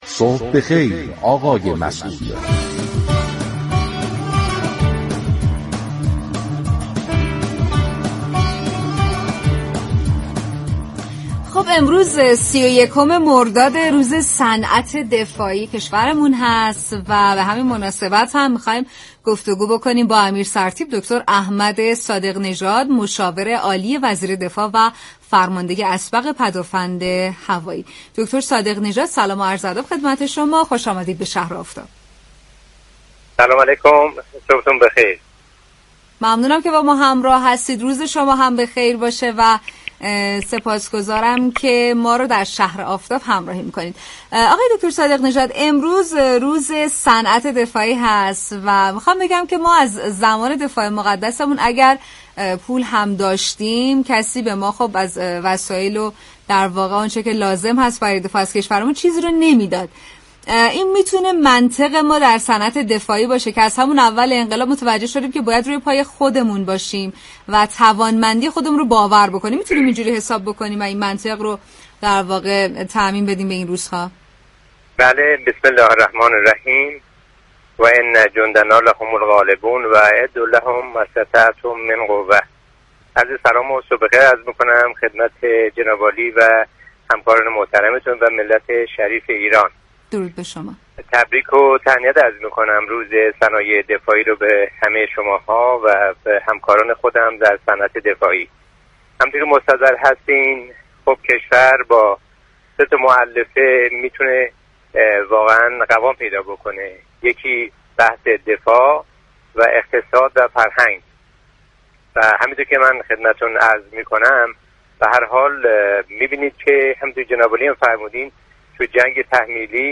به گزارش پایگاه اطلاع رسانی رادیو تهران، برنامه شهر آفتاب رادیو تهران امروز 31 مرداد ماه به مناسبت روز صنعت دفاعی، با امیر سرتیپ دكتر احمد صادق نژاد مشاور عالی وزیر دفاع و فرمانده اسبق پدافند هوایی به گفت‌وگو پرداخت.